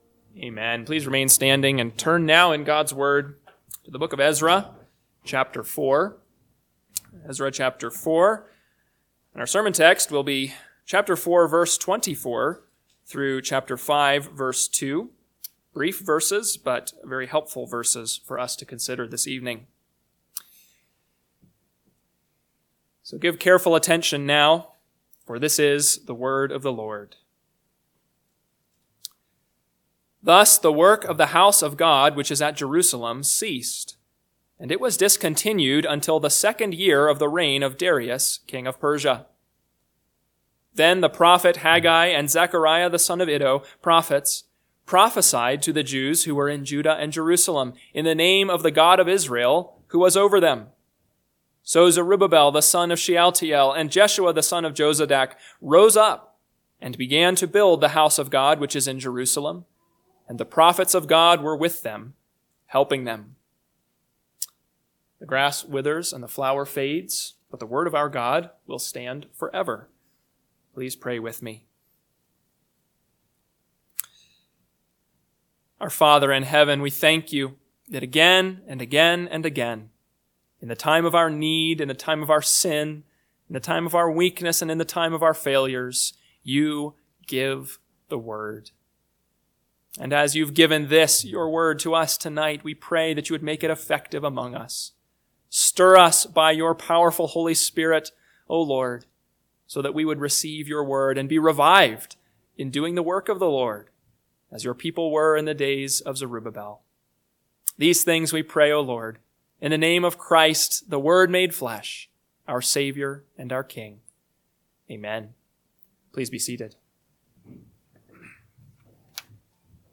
PM Sermon – 3/30/2025 – Ezra 4:24-5:2 – Northwoods Sermons